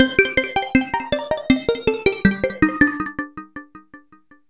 Ambient / Keys / SYNTH033_AMBNT_160_C_SC3.wav